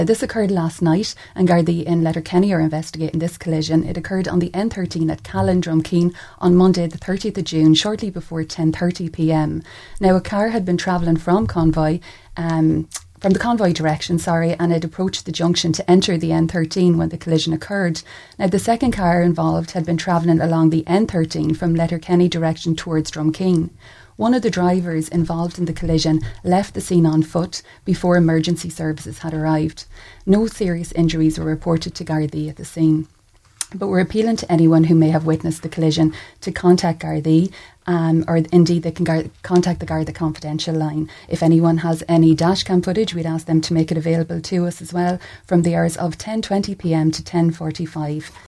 appeal for information